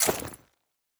Foley Armour 01.wav